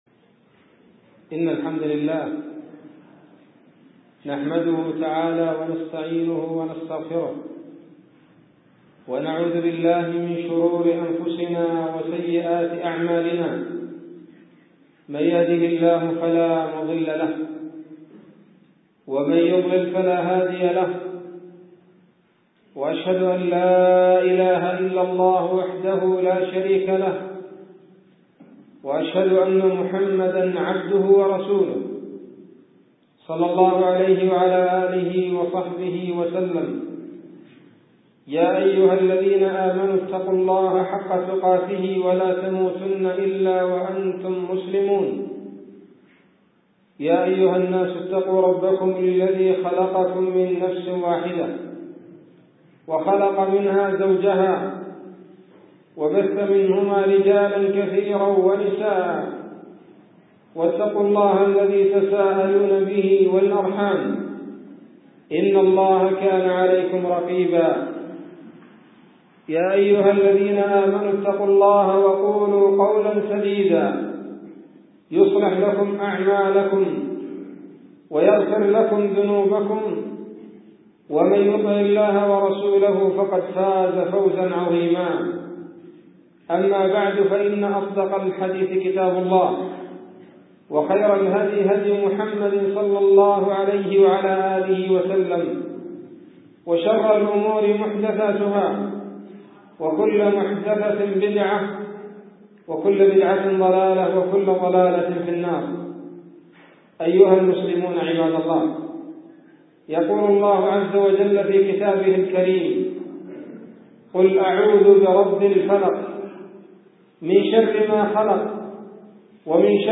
خطبة بعنوان : ((الحسد))